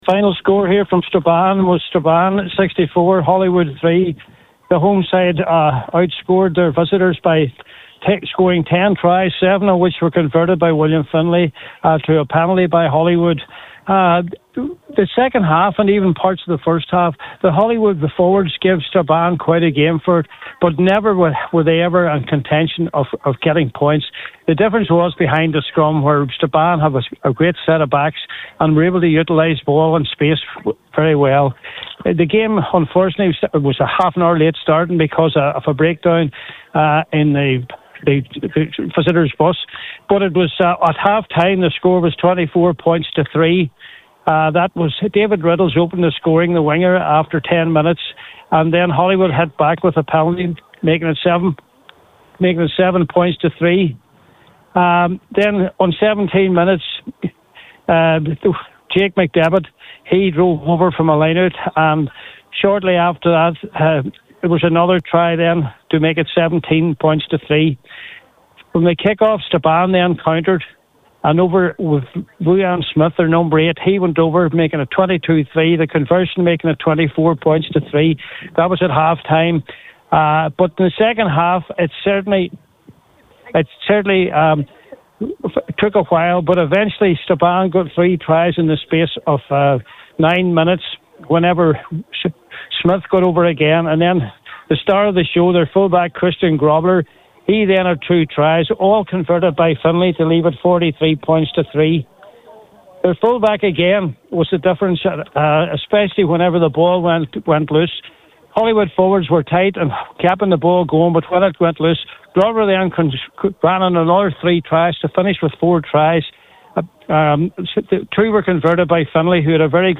full time report